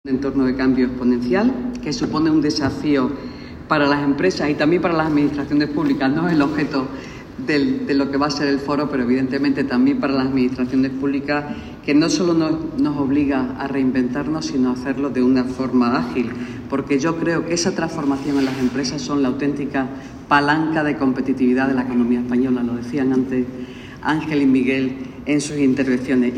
La delegada de Economía, Innovación y Hacienda ha participado en la presentación de la segunda edición de Madrid Leaders Forum
Engracia Hidalgo Forums Leader declas 1.mp3